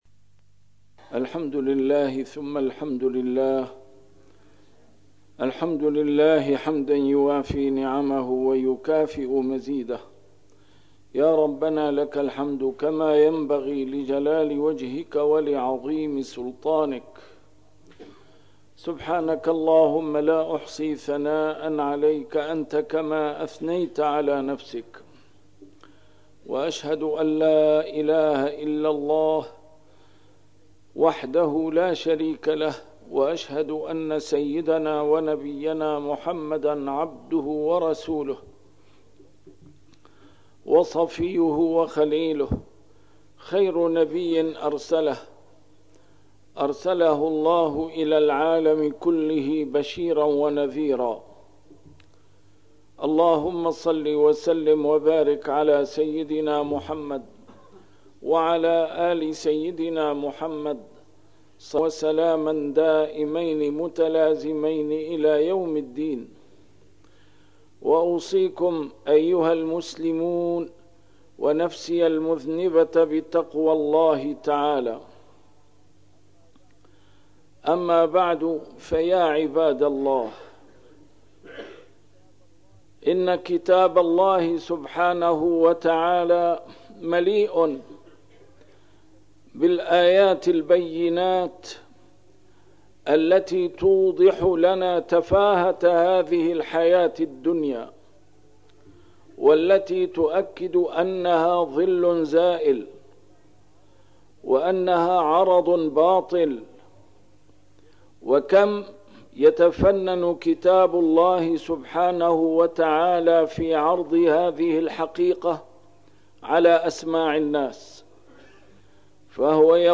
نسيم الشام › A MARTYR SCHOLAR: IMAM MUHAMMAD SAEED RAMADAN AL-BOUTI - الخطب - متى يدرك الإنسان حقيقة الدنيا؟!